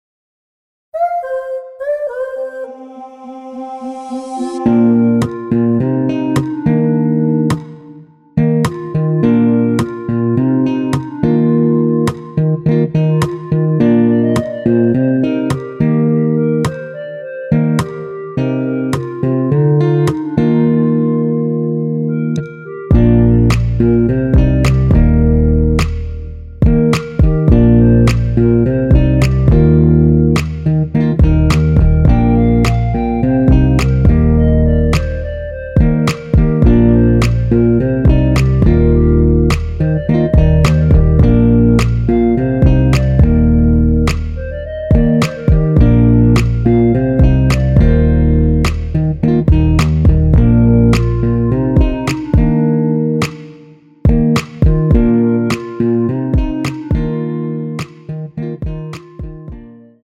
원키에서(-1)내린 멜로디 포함된 MR입니다.(미리듣기 확인)
Bb
멜로디 MR이라고 합니다.
앞부분30초, 뒷부분30초씩 편집해서 올려 드리고 있습니다.
중간에 음이 끈어지고 다시 나오는 이유는